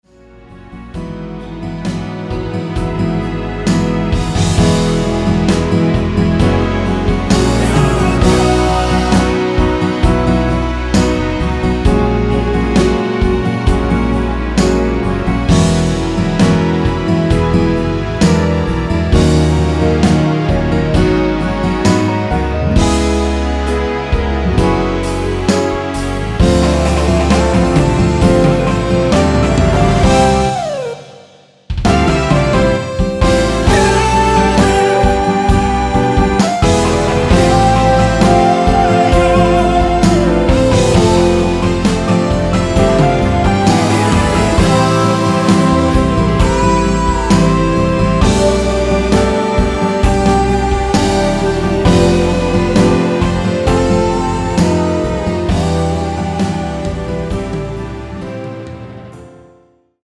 원키에서(-2)내린 코러스 포함된 MR 입니다.(미리듣기 참조)
Ab
앞부분30초, 뒷부분30초씩 편집해서 올려 드리고 있습니다.